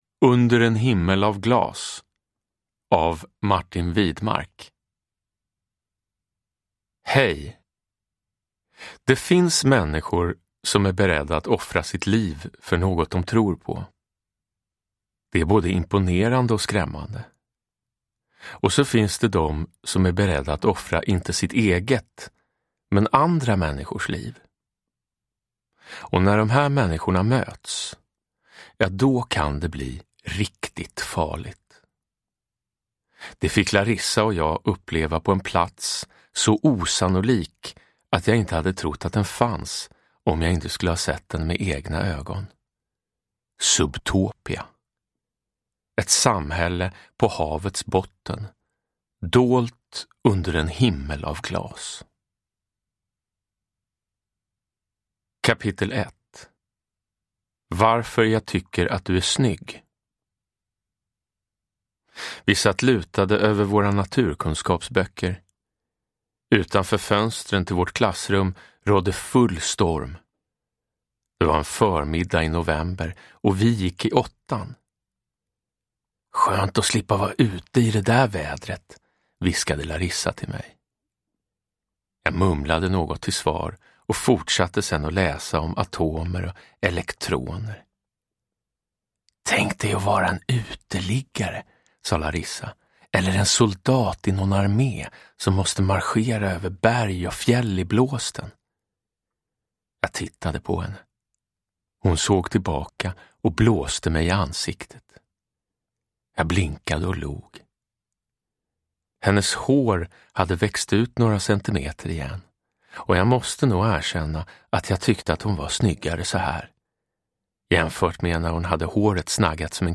Under en himmel av glas – Ljudbok – Laddas ner
Uppläsare: Jonas Karlsson